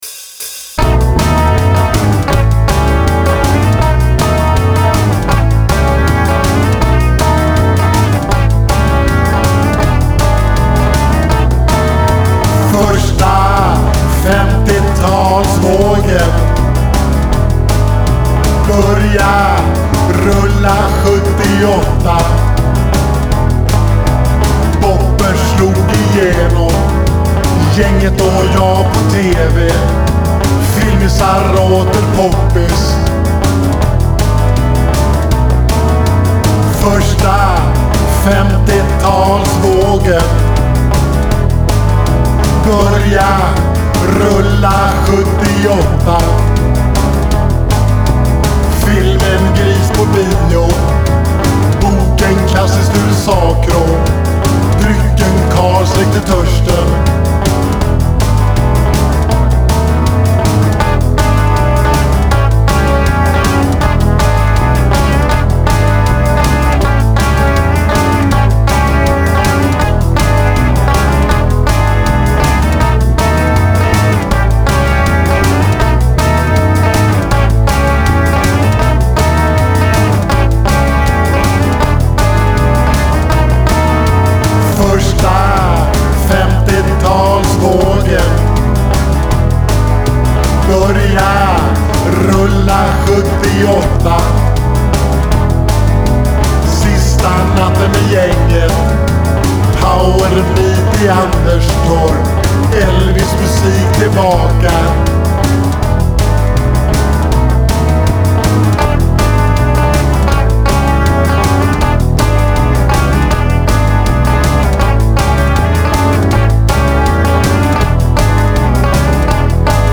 En sådan text borde ju tonsättas av en rocklåt.
Nu fick jag min egen 16-taktsrunda!